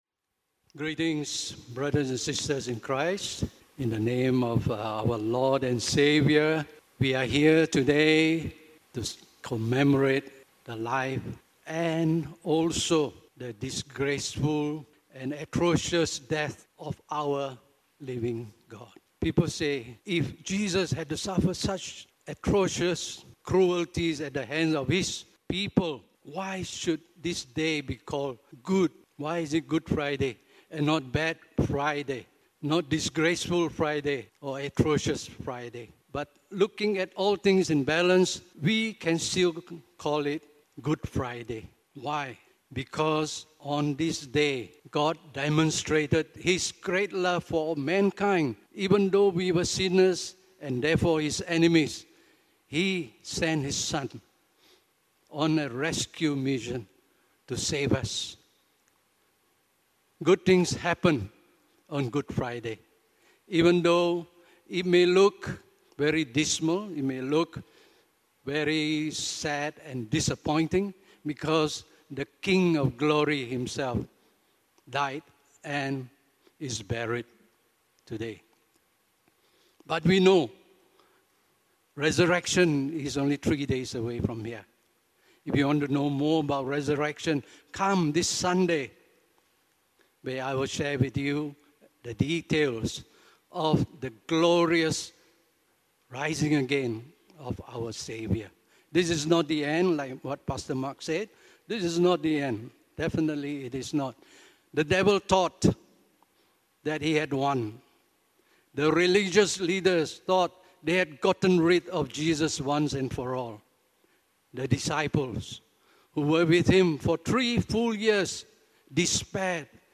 The Darkest Hour, the Greatest Love – Good Friday Message